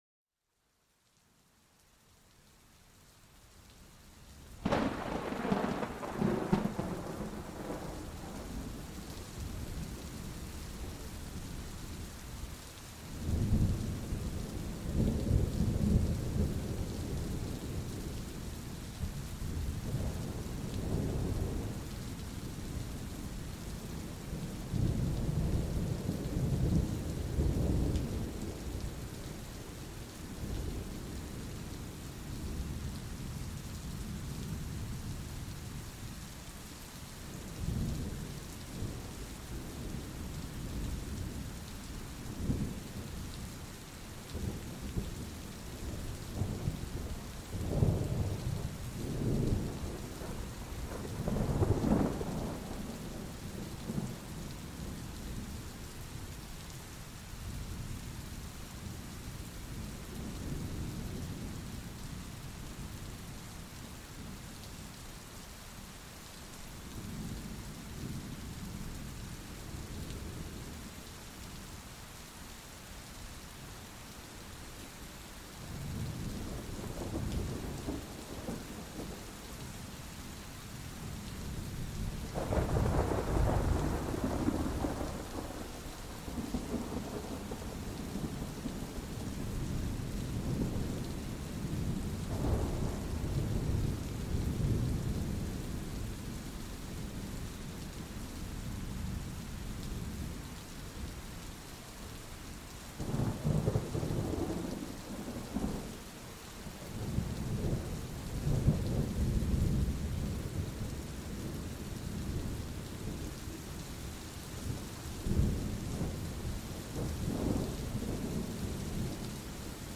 Escucha el retumbar del trueno y la caída de la lluvia, creando una atmósfera perfecta para la relajación.
Cada episodio presenta grabaciones auténticas para ayudarte a relajarte y dormir.